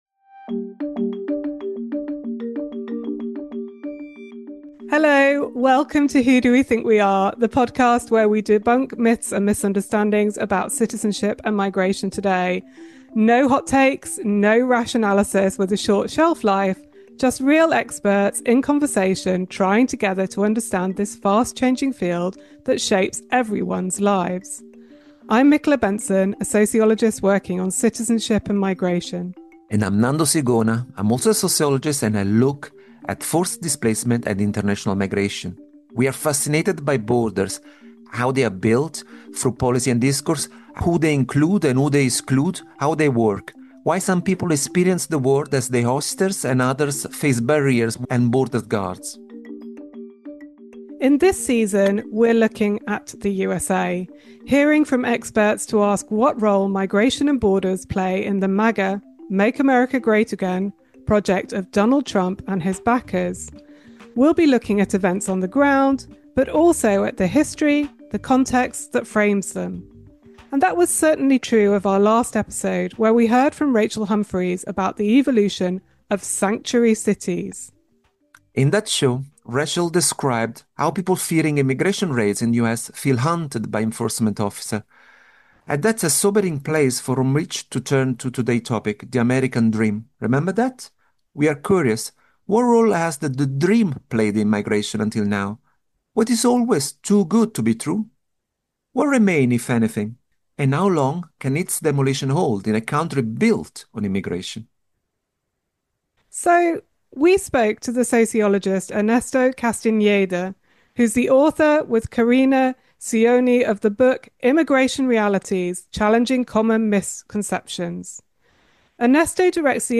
A myth-busting conversation on hope, solidarity and change.